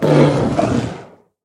Minecraft Version Minecraft Version 25w18a Latest Release | Latest Snapshot 25w18a / assets / minecraft / sounds / mob / polarbear / death1.ogg Compare With Compare With Latest Release | Latest Snapshot